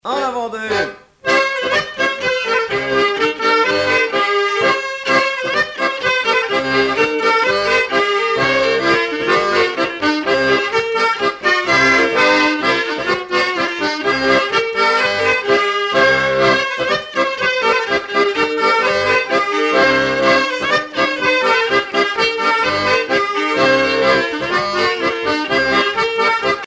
danse : branle : avant-deux
Pièce musicale éditée